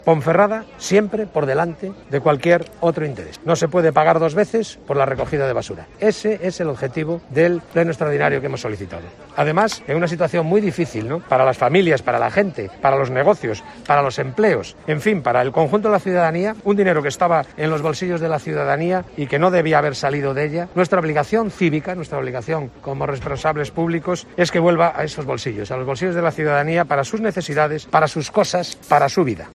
Samuel Folgueral, de Use Bierzo, ha sido un poco el portavoz para explicar la solicitud que han realizado